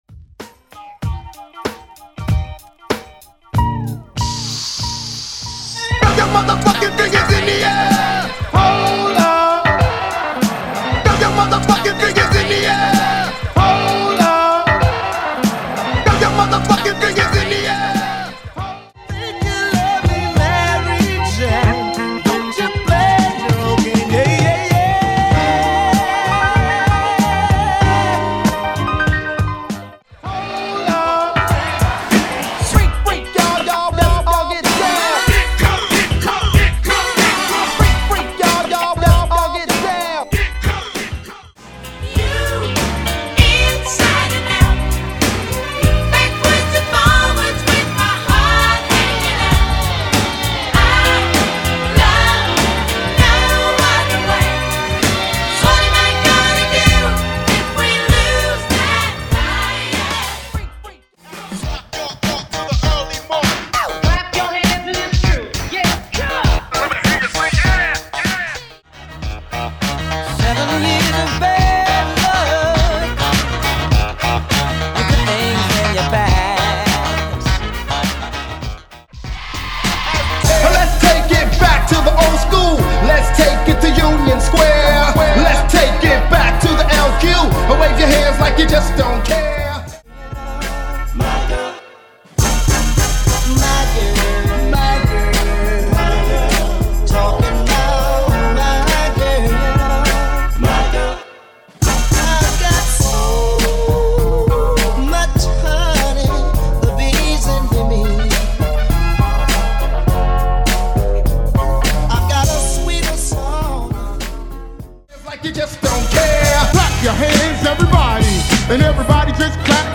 Genre: ROCK
Clean BPM: 128 Time